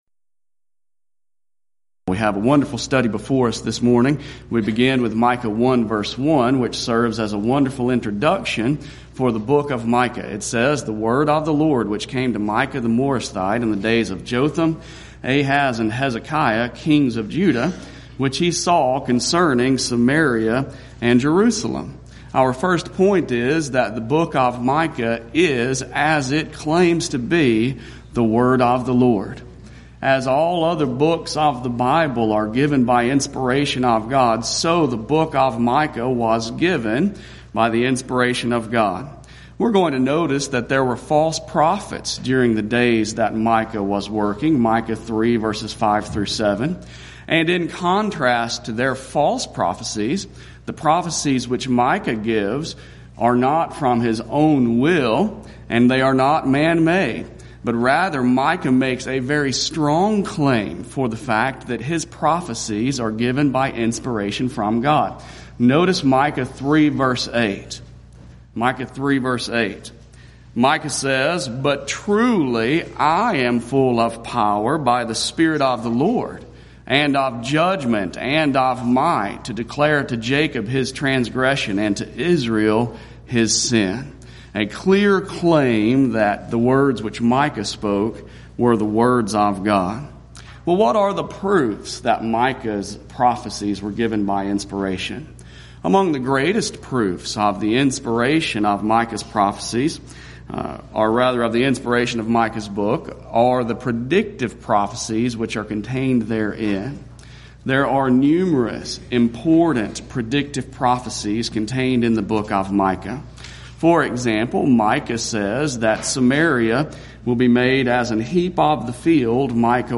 Event: 12th Annual Schertz Lectures Theme/Title: Studies in the Minor Prophets